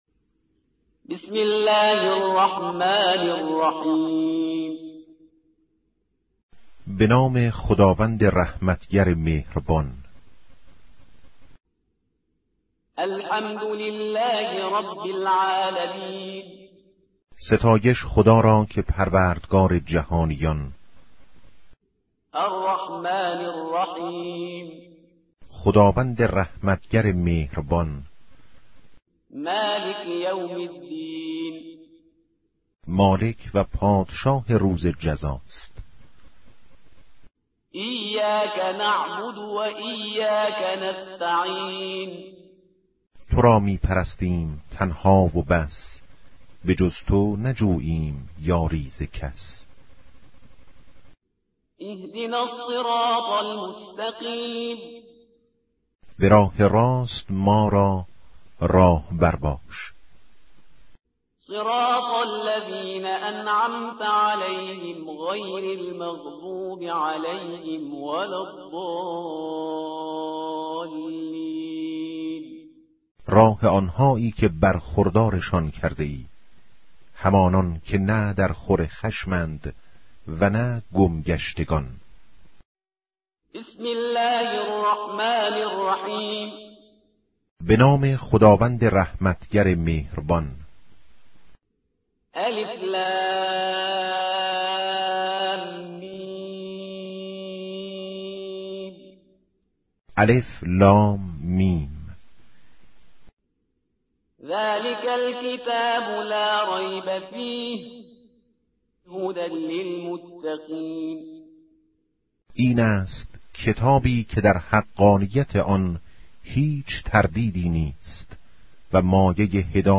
دانلود ترتیل قرآن کریم همراه با ترجمه گویا فارسی